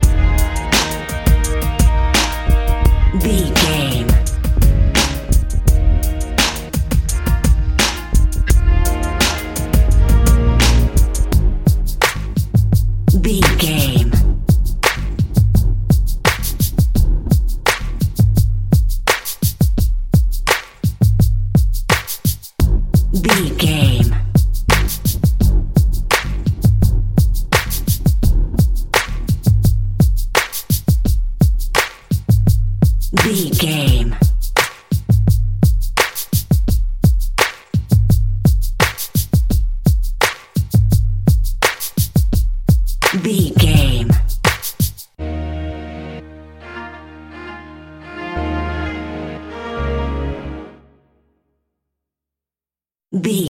Epic / Action
Aeolian/Minor
E♭
drum machine
synthesiser
funky
hard hitting